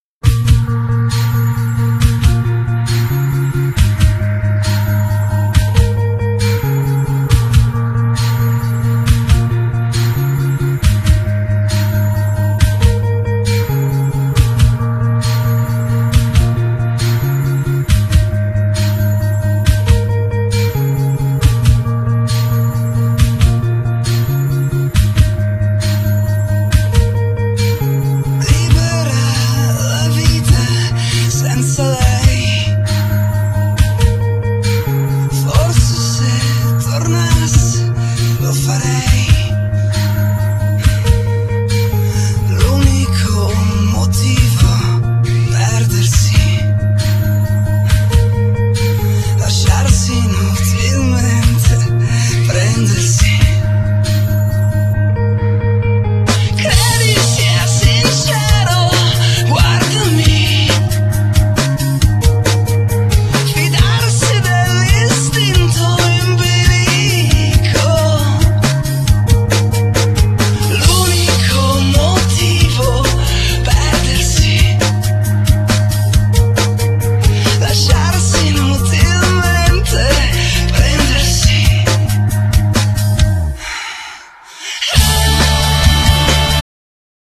Genere: Pop
chitarra e voce
bassista
batterista